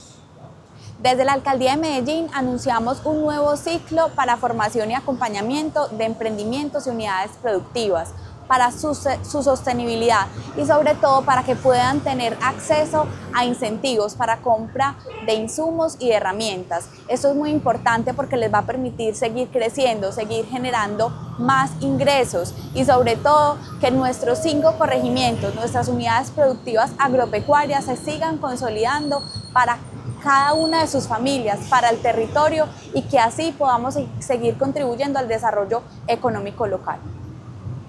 Audio Declaraciones de la secretaria de Desarrollo Económico, María Fernanda Galeano La Alcaldía de Medellín abre las inscripciones para que los productores agropecuarios de los cinco corregimientos accedan a recursos para la compra de insumos y herramientas.
Audio-Declaraciones-de-la-secretaria-de-Desarrollo-Economico-Maria-Fernanda-Galeano.mp3